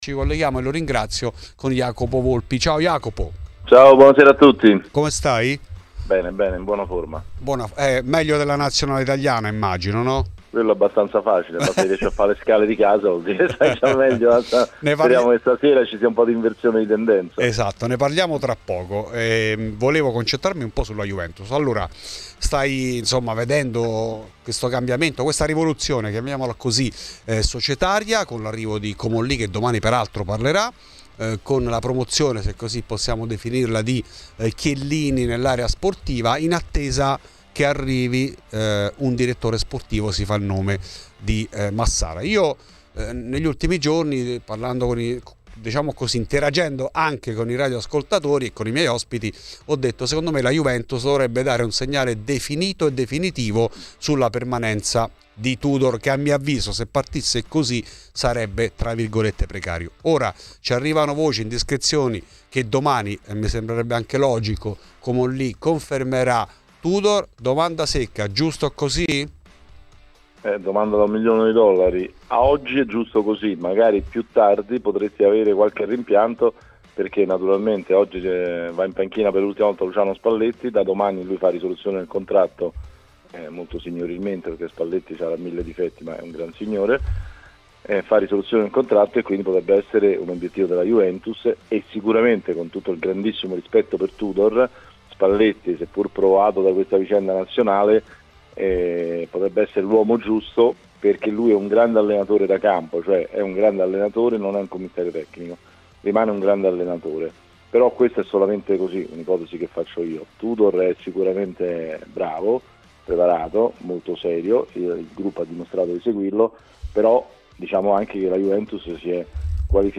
I giocatori sono molto stanchi e se non foisse per la questione economica, direi che questa competizione diventa un fastidio" Per l'intervista completa ascolta il podcast